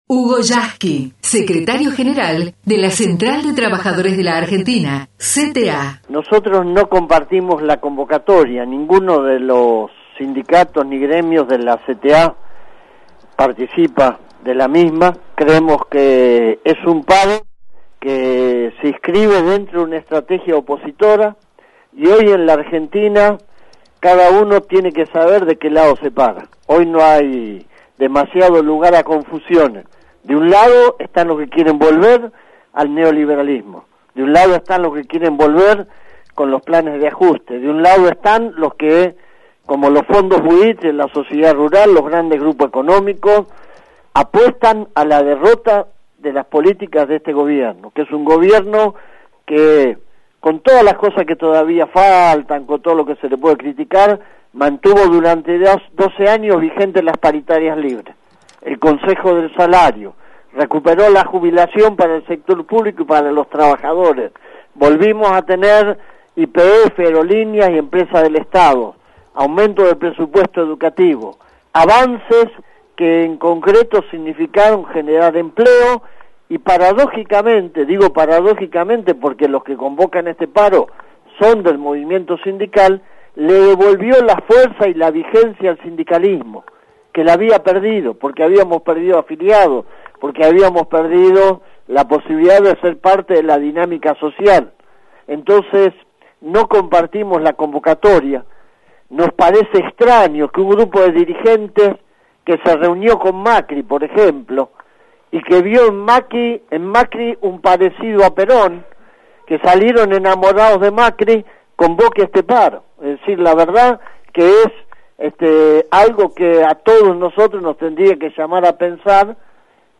HUGO YASKY entrevistado en RADIO TELAM